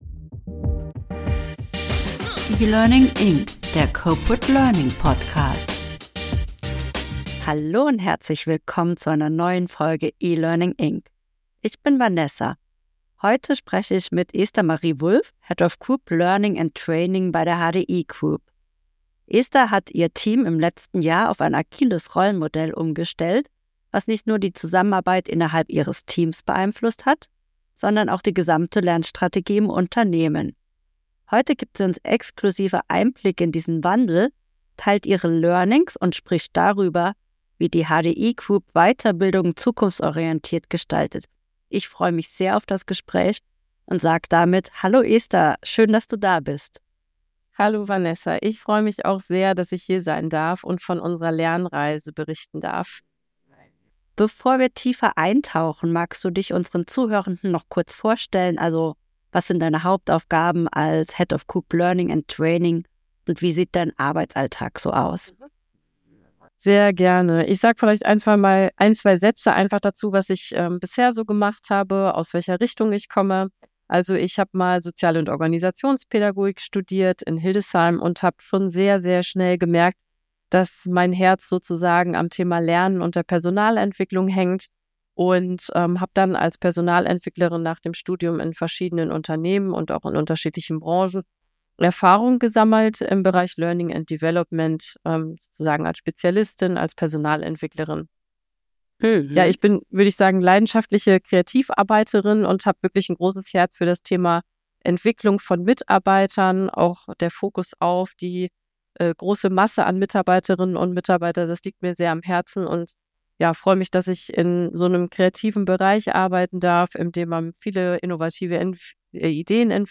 Takeaways aus dem Interview: Ziel der Umstellung auf ein agiles Learning & Development-Team war es, die Lernkultur weiterzuentwickeln und einen Beitrag zur lernenden Organisation zu leisten.